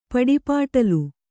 ♪ paḍi pāṭalu